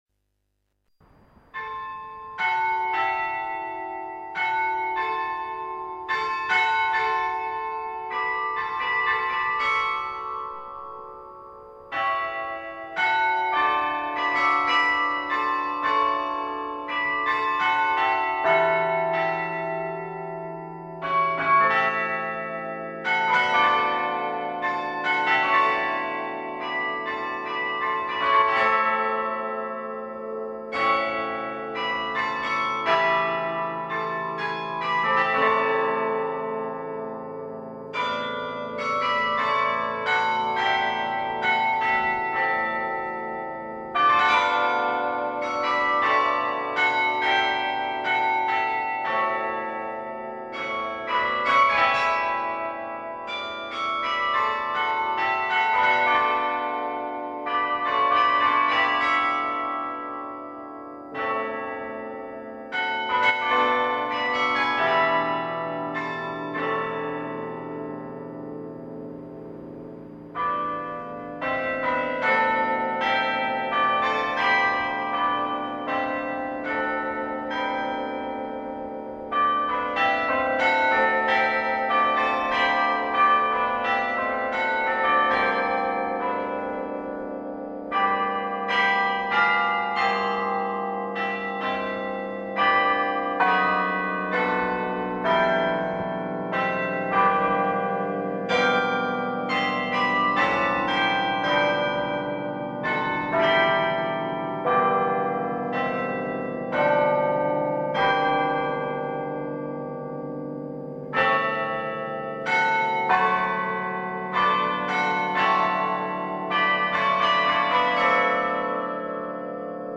Fleischmann Carillon Recital 13 June 2010 in St Colman’s Cathedral, Cobh
Fleischmann: A Traditional Air ‘Eibhlín a Rúin’